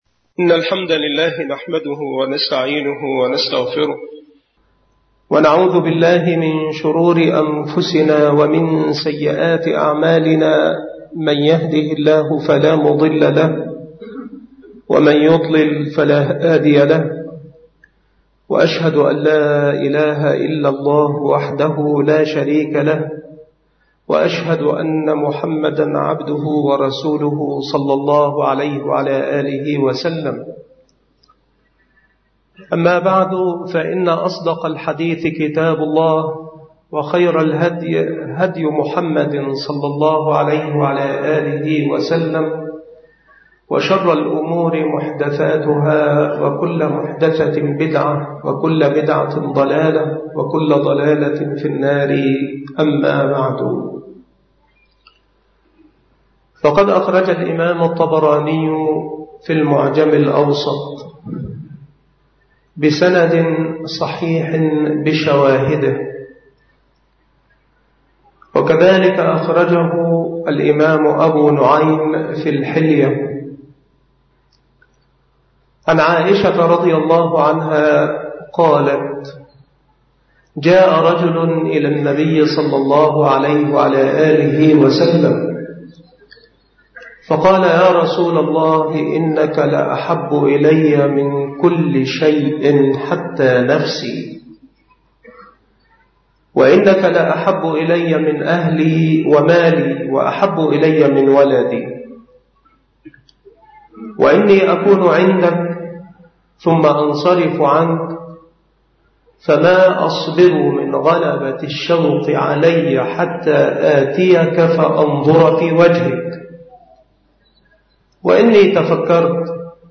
المحاضرة
مكان إلقاء هذه المحاضرة بمسجد عزبة المعاشات - سبك الأحد - أشمون - محافظة المنوفية